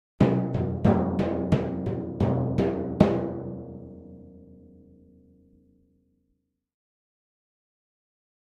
Timpani, (Hands), Accent, Symphonic Finale Hits, Type 3